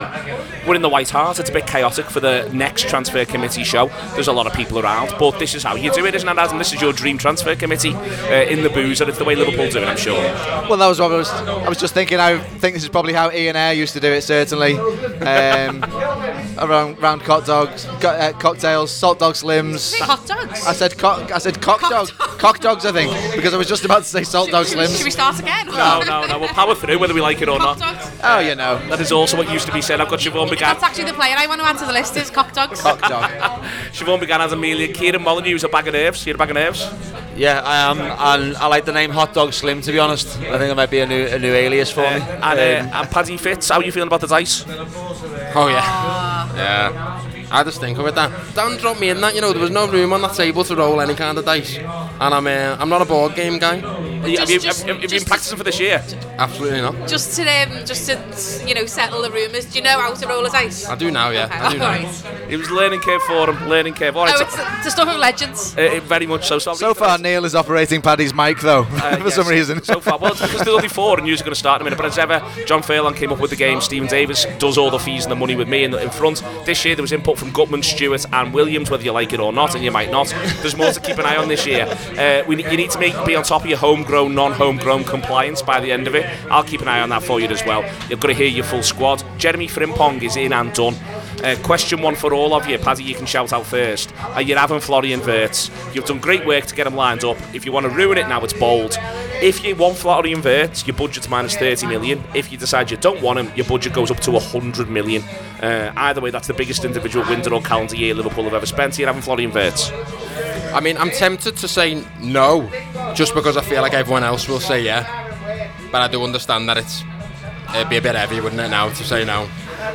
Below is a clip from the show – subscribe for more Liverpool FC transfer committee games…